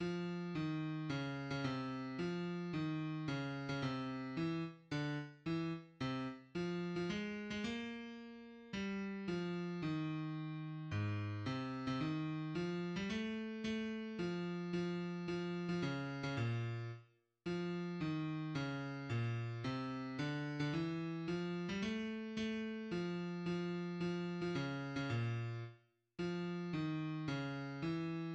{\clef bass \tempo 4 = 110 \key aes \major \set Score.currentBarNumber = #13 \bar "" f ees des8. des16 c4 f ees des8. des16 c4 e-. d-. e-. c-. f8. f16 aes8. aes16 bes2 g4 f ees2 aes,4 c8. c16 ees4 f8. g16 a4 a f f f8. f16 des8. des16 bes,4 r4 f ees des bes, c d8. d16 e4 f8. g16 a4 a f f f8. f16 des8. des16 bes,4 r4 f ees des e }\addlyrics {\set fontSize = #-2 Zog nit key - nmol, Zog nit key - nmol, Zog nit key- nmol geyst dem let- stn veg Him- len far- shteln bloy- e teg Ku- men vet nokh un- zer oys- ge- benk- te sho Trot mir zayn- en do zayn- en do Ku- men vet nokh un- zer oys- ge- benk- te sho un- zer trot Ge } \addlyrics {\set fontSize = #-2 shri- ben is dos lid blut un nit mit blay iz dos lid mit blut un nit mit blay Fun a foy gl oyf der fray, S'hot a folk ts'- vi- shn fa- ln- di- ke vent, Mit na- gan- es hent in di hent }\midi{}